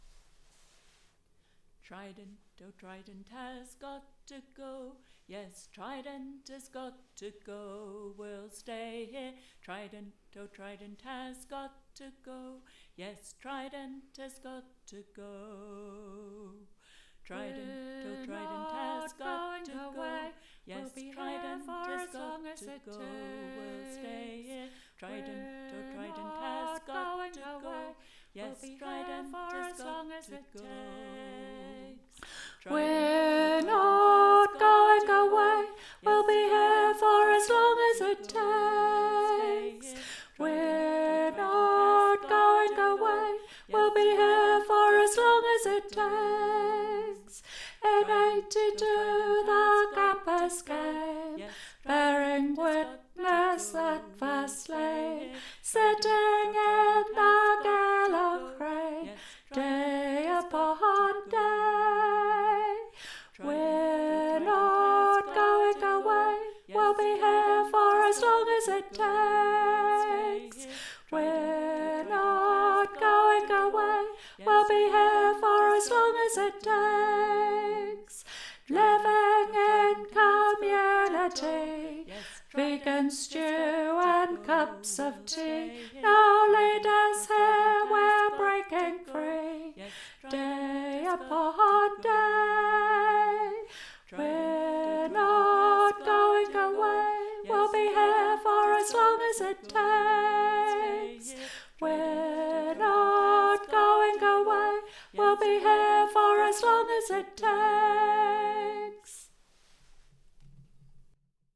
High: